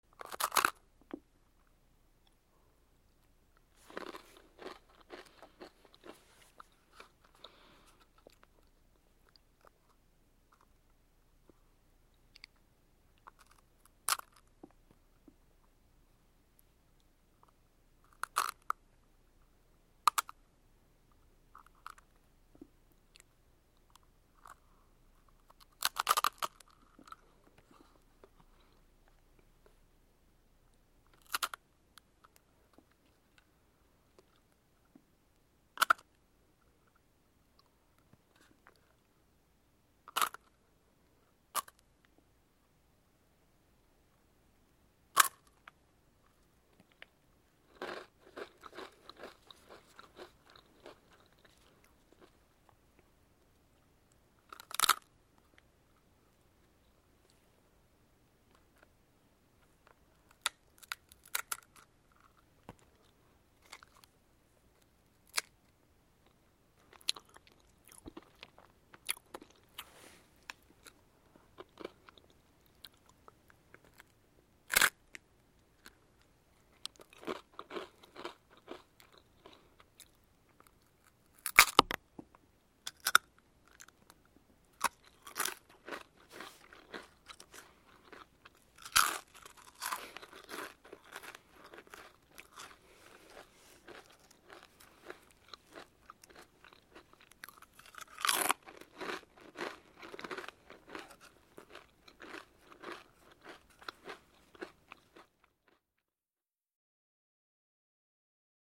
Хрустящий звук поедания мороженого с вафельным рожком